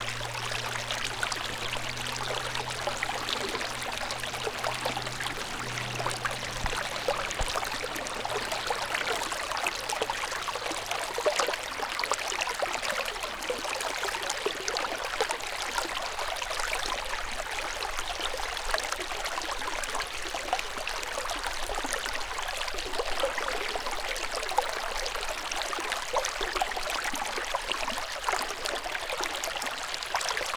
Sounds of the Escondido Creek Watershed
Escondido Creek Water Flow
Escondido Creek water flow - light.wav